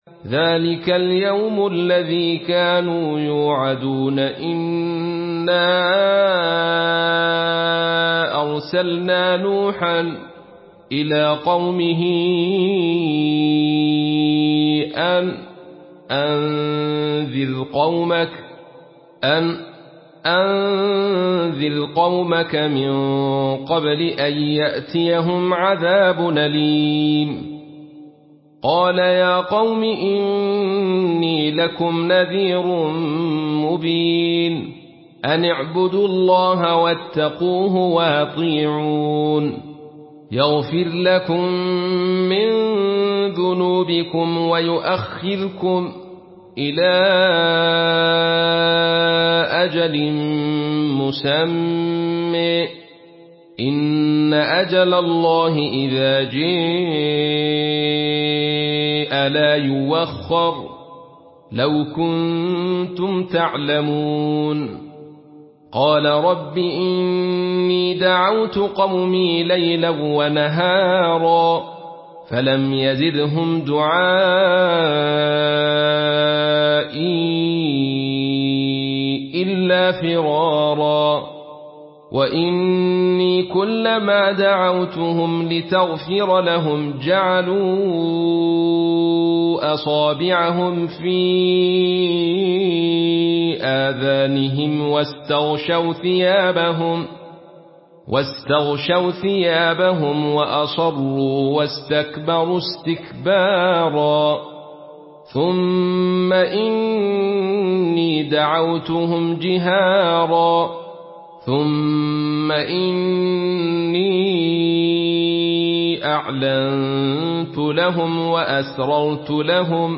Murattal Khalaf An Hamza